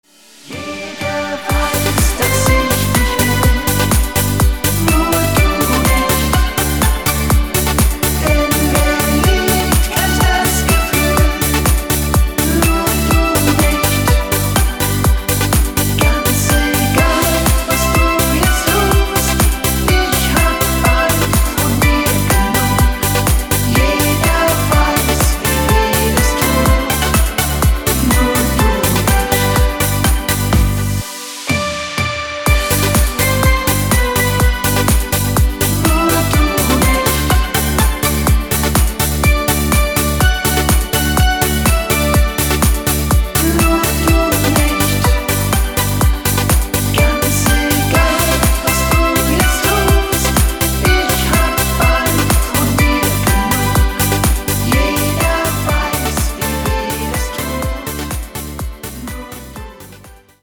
Top Schlager für die Sängerin